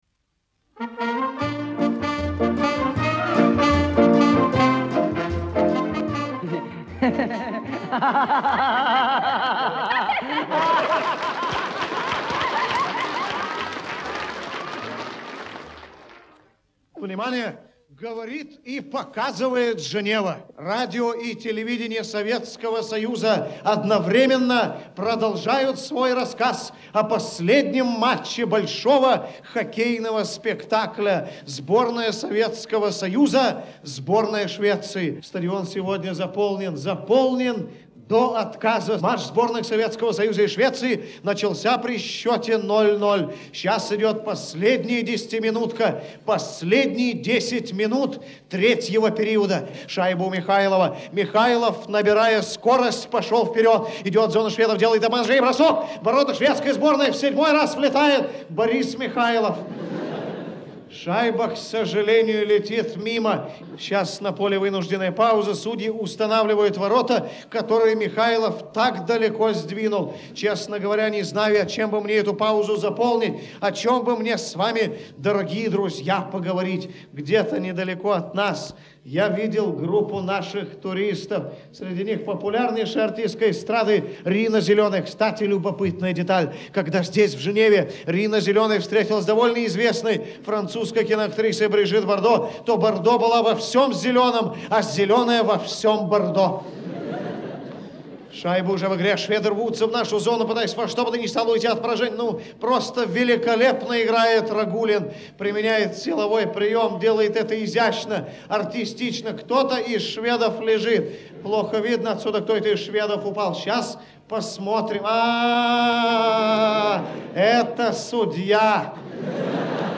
Три пародии (Озеров, Литвинов, Жюв).